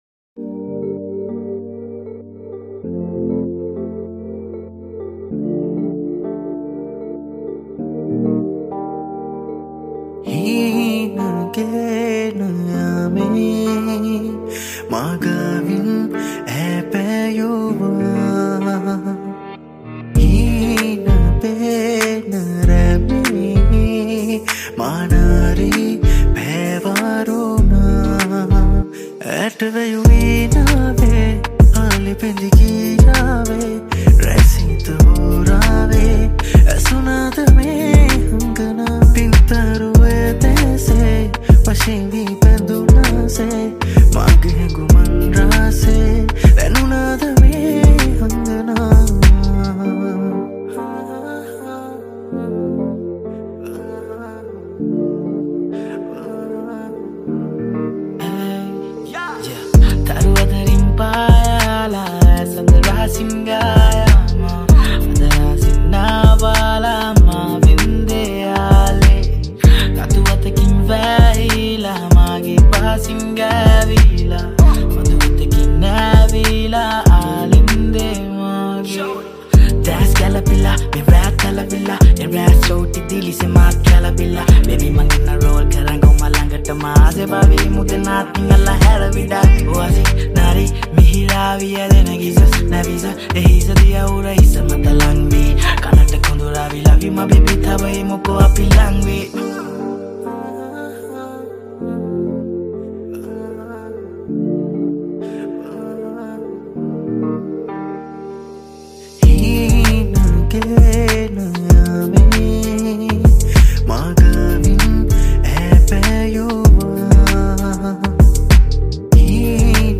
sinhala rap
sri lankan hiphop music